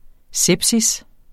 Udtale [ ˈsεbsis ]